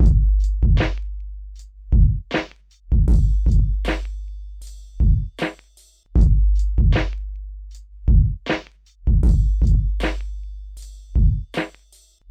Loops, breaks
kickinitoop78bpm.wav